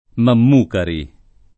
[ mamm 2 kari ]